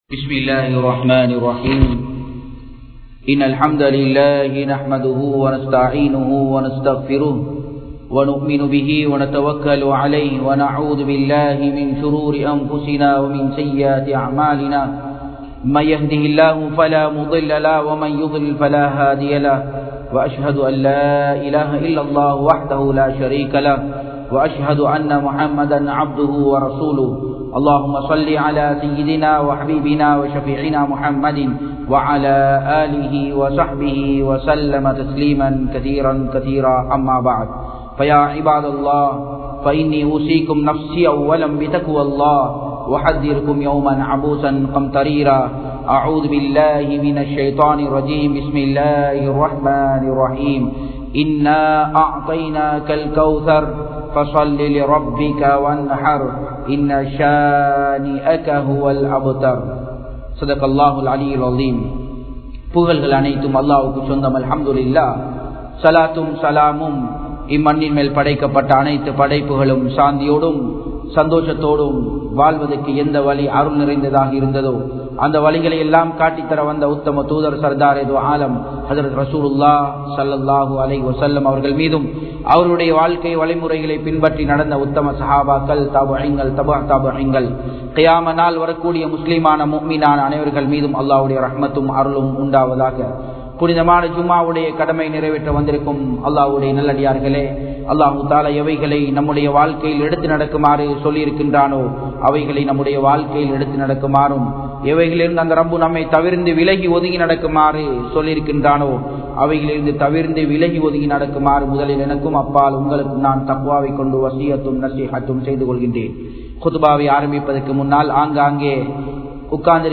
Ibrahim(Alai)Avarhalukku Eatpatta Soathanaihal (இப்றாஹிம்(அலை) அவர்களுக்கு ஏற்பட்ட சோதனைகள்) | Audio Bayans | All Ceylon Muslim Youth Community | Addalaichenai
Japan, Nagoya Port Jumua Masjidh 2017-09-01 Tamil Download